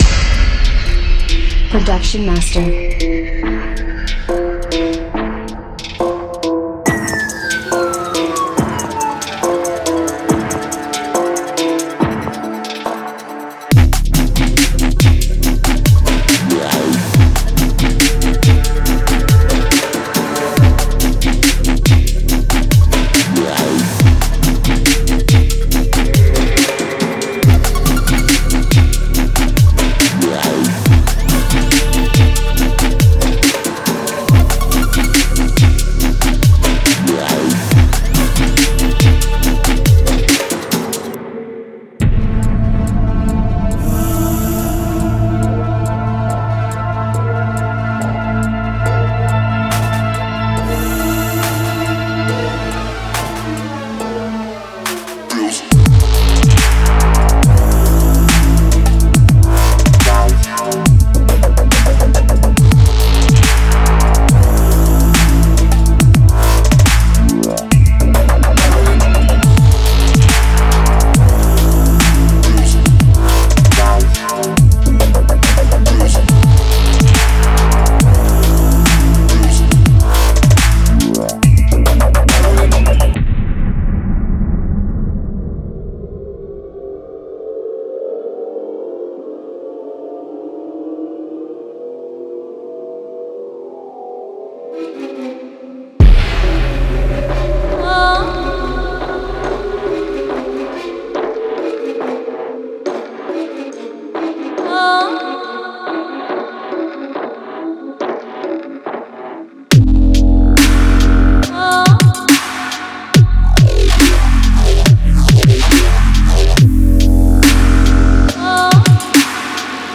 可怕的氛围
通过将这些邪恶的氛围无人机循环和恶意的氛围合成器循环添加到您的作品中，为所有轨道提供完整的地下声音。
粉碎的脚踢，圈套的军鼓和整洁的帽子随时可以为您的下一个作品增光添彩。
这些鼓绝对残酷无比，是专为这项工作而制作的！